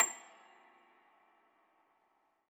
53k-pno29-A6.wav